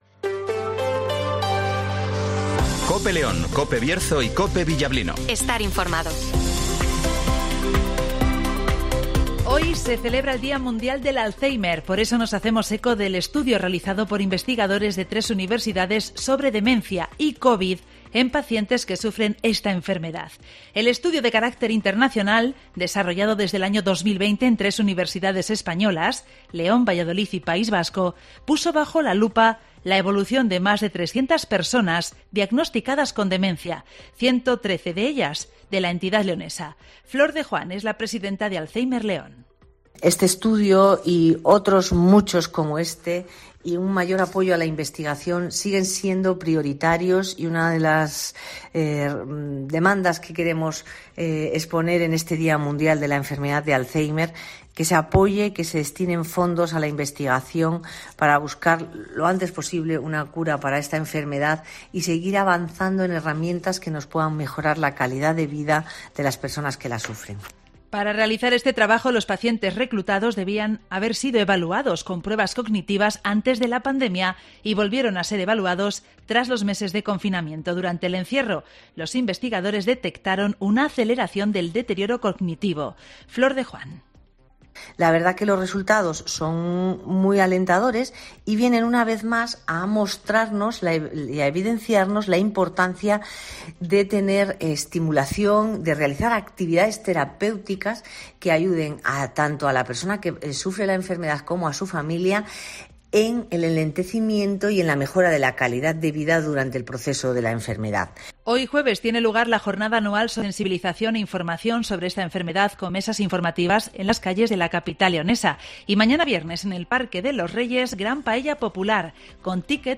Informativo Mediodia en COPE León, Bierzo y Villablino (21/09/2023)